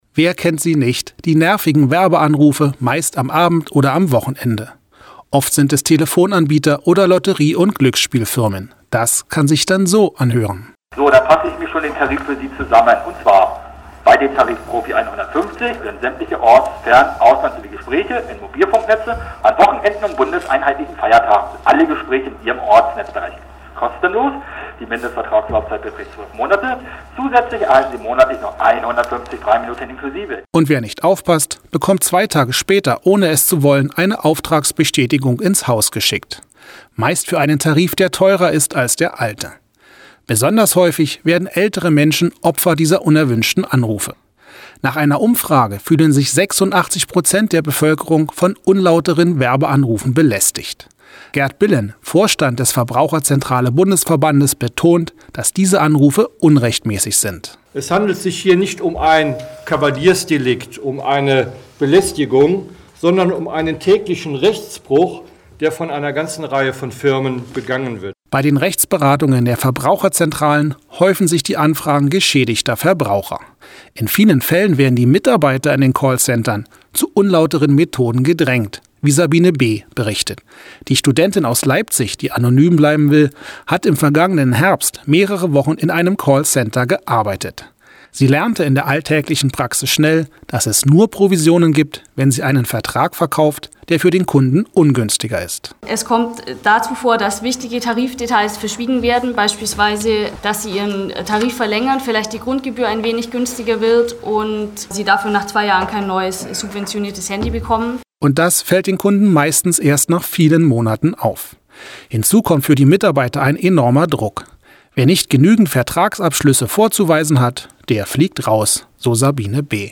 O-Ton von Wallraff